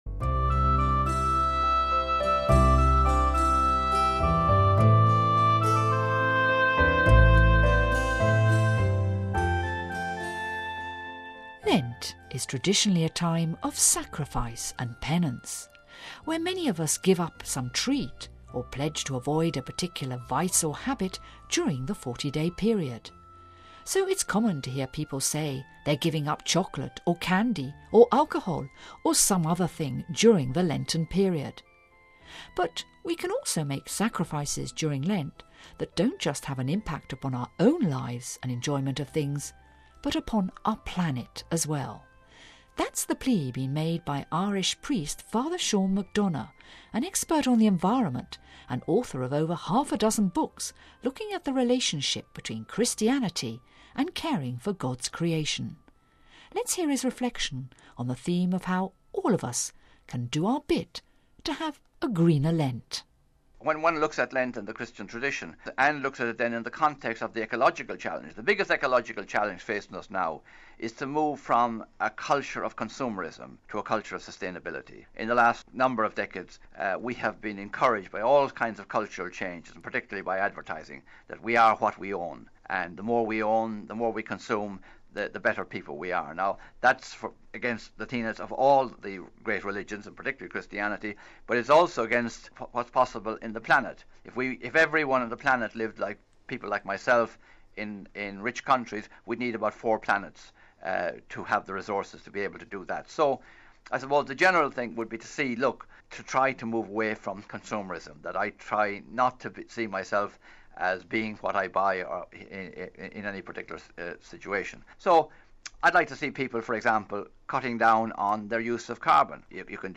Home Archivio 2010-03-12 14:53:39 A GREENER LENT An Irish Colomban priest and renowned ecologist and author reflects on how we can all make small sacrifices during this Lenten season to help protect the environment.....